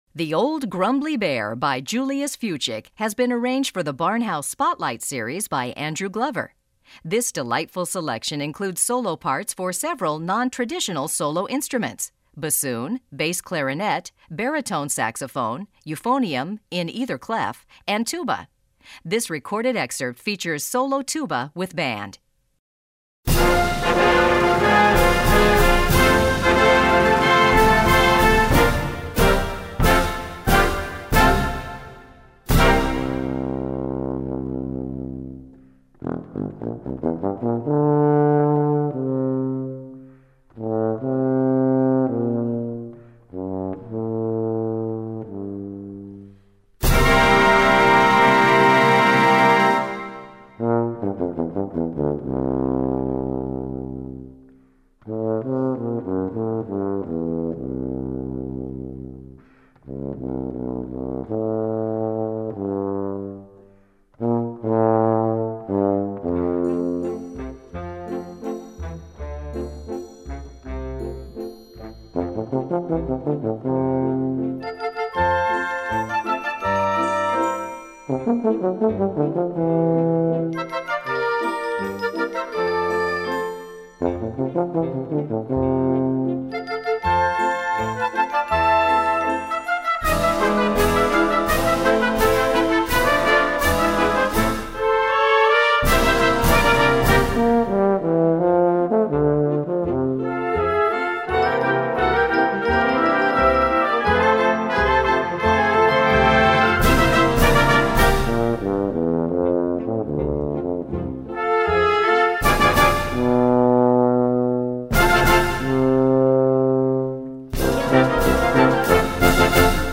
Gattung: Solo für Bassklarinette, Baritonsax, ...
Besetzung: Blasorchester